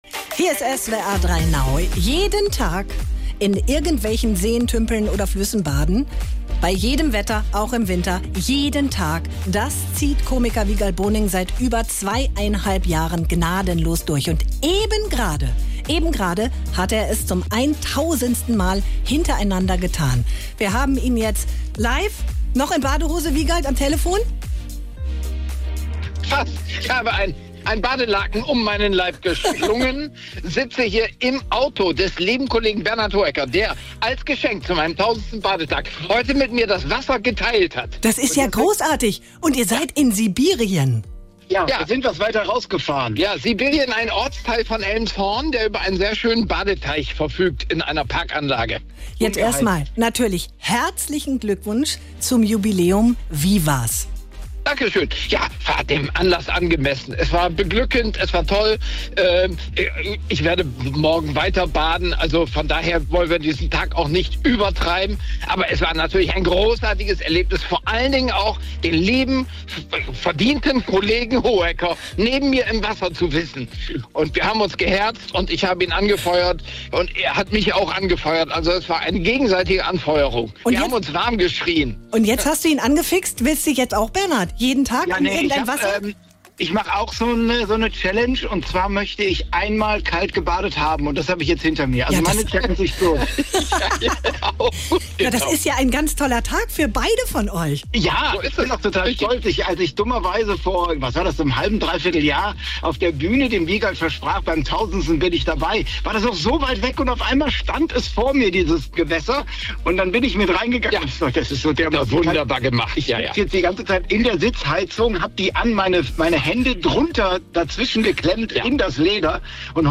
Von seinem 1000. Badetag hat er live bei SWR3 erzählt – direkt vom See.
Und direkt danach live in SWR3 NOW